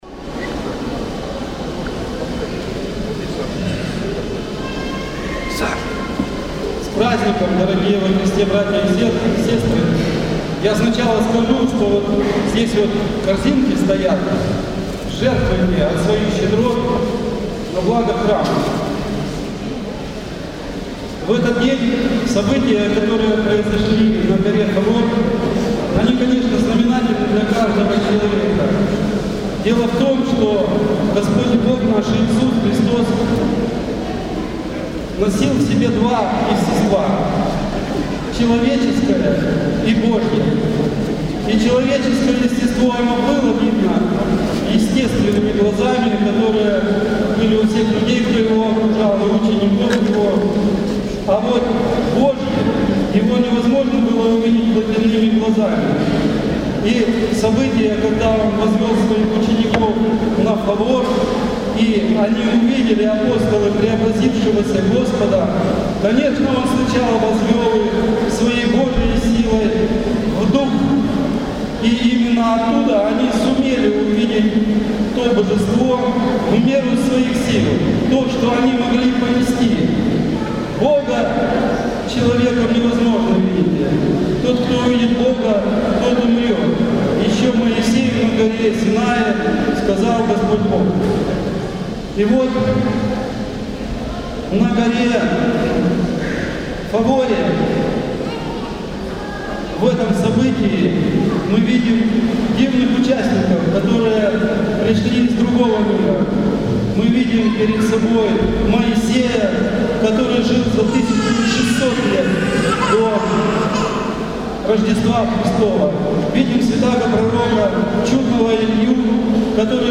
НОВОСТИ, Проповеди и лекции